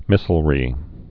(mĭsəl-rē)